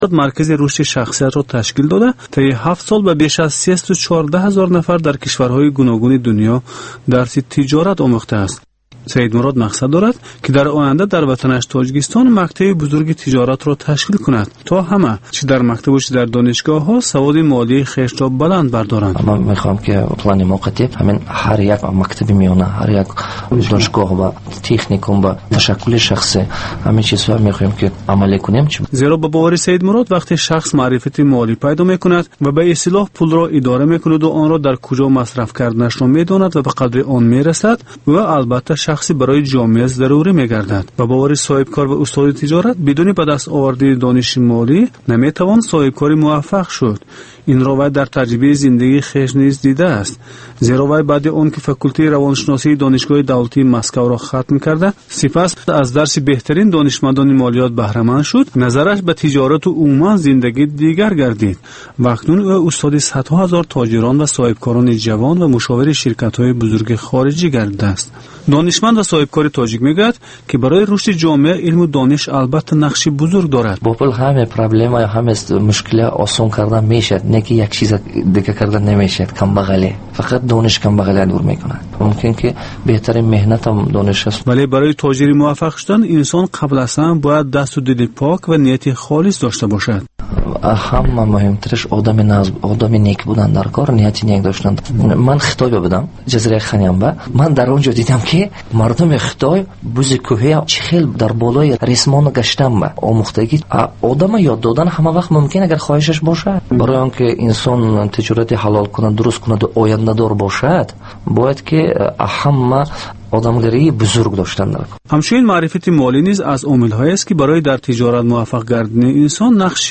Маҷаллаи ғайриодӣ, ки ҳамзамон дар шакли видео ва гуфтори радиоӣ омода мешавад.